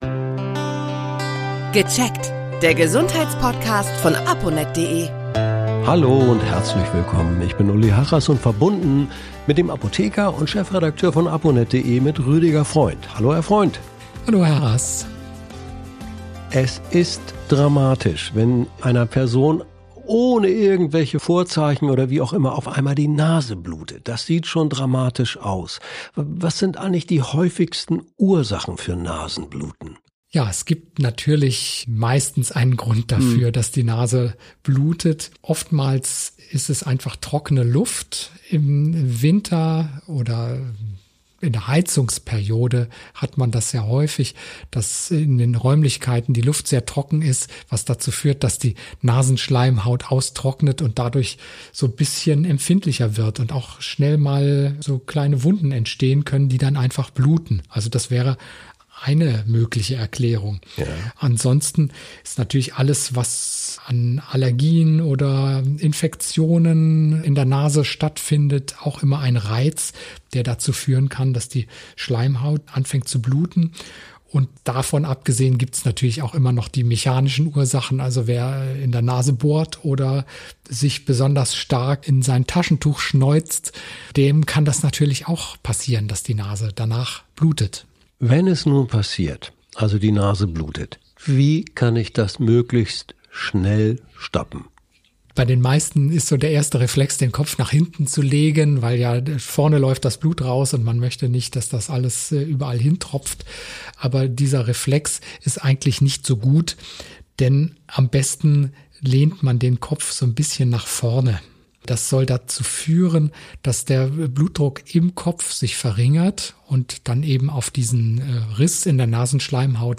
Wie sich die Blutung schnell stoppen lässt, erklärt ein Apotheker.